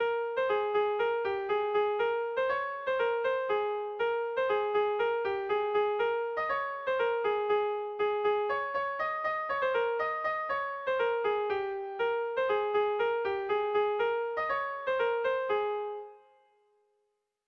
Bertso melodies - View details   To know more about this section
Irrizkoa
Zortziko ertaina (hg) / Lau puntuko ertaina (ip)
A1A2BA2